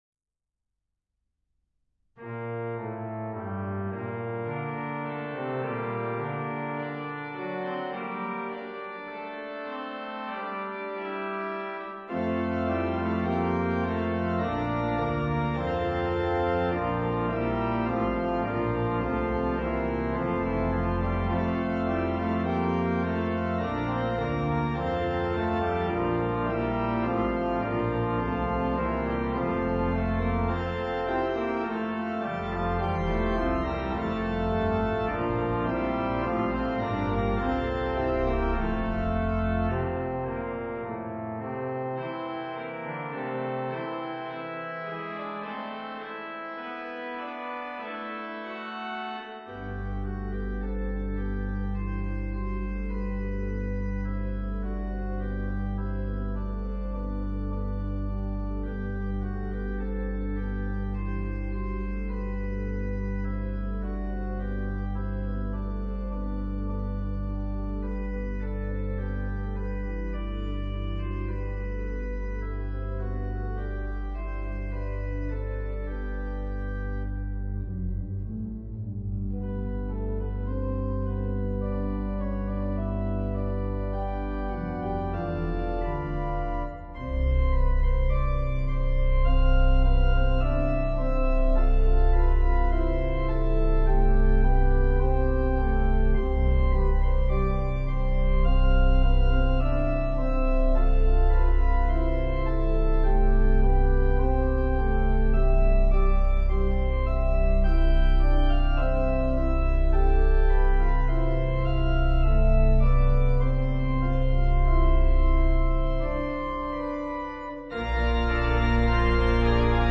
Variations include: Trumpet Tune, Duo, Canon, and Toccata.